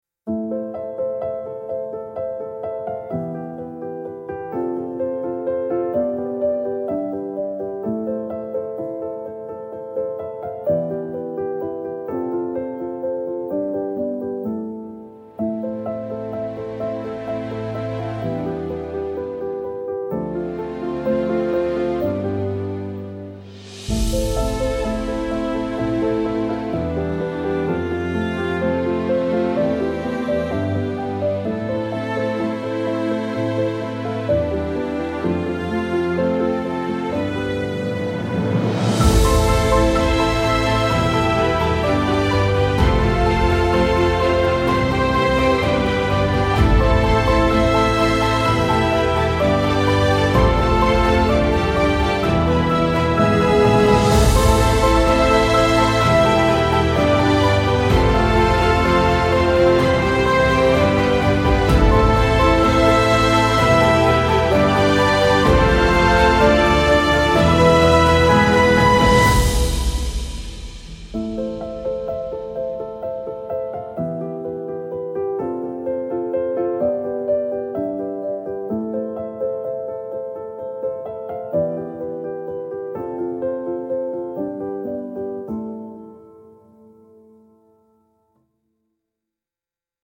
film score style love theme with lush orchestra and emotional crescendo